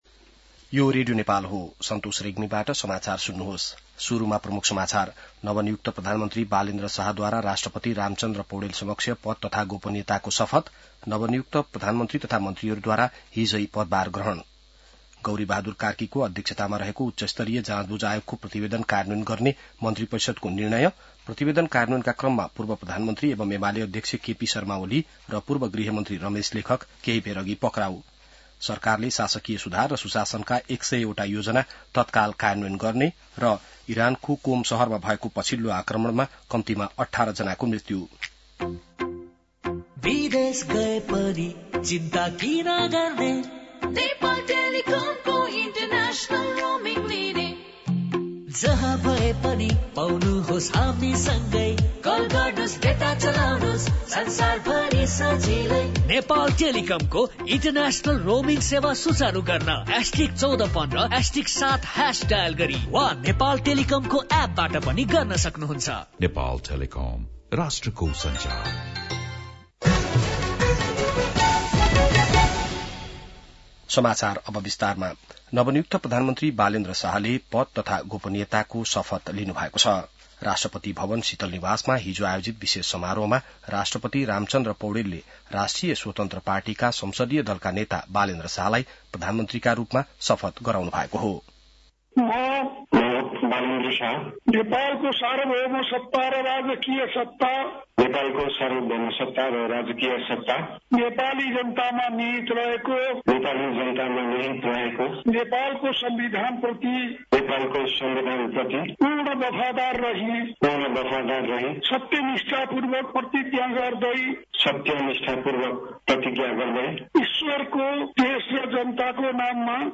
An online outlet of Nepal's national radio broadcaster
बिहान ७ बजेको नेपाली समाचार : १४ चैत , २०८२